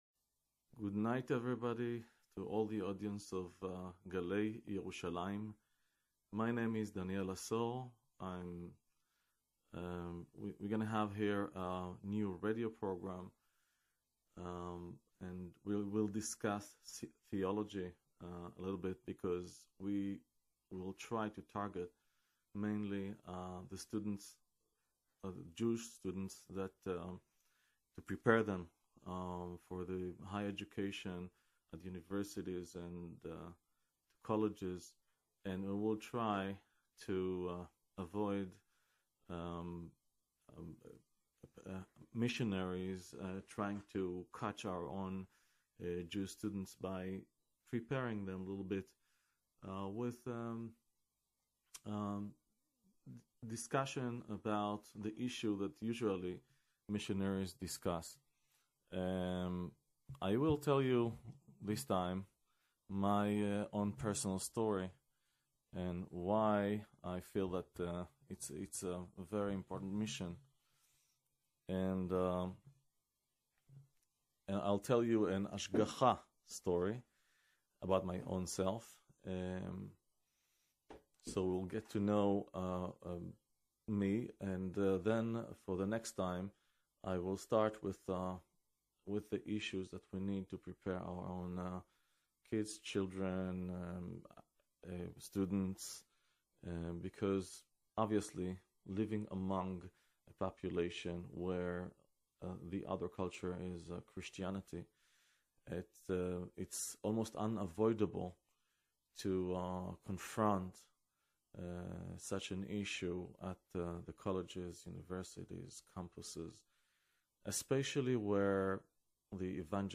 Radio program (part 1)